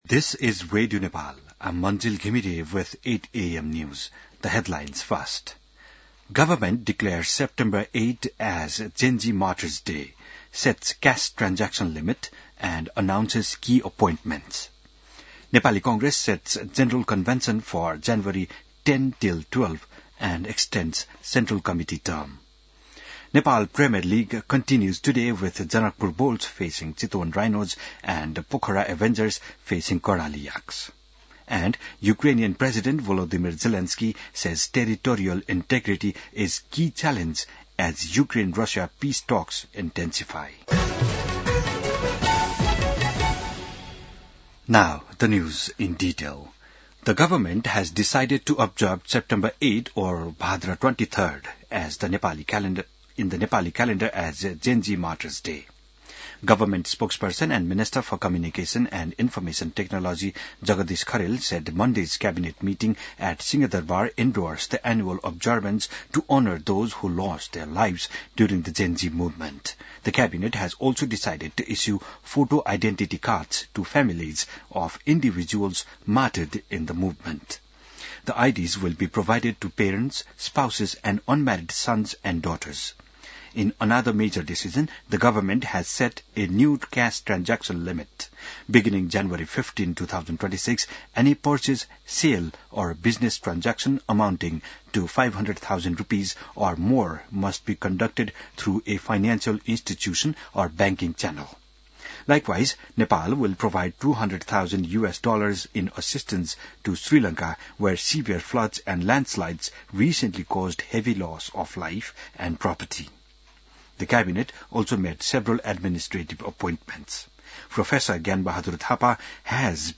बिहान ८ बजेको अङ्ग्रेजी समाचार : १६ मंसिर , २०८२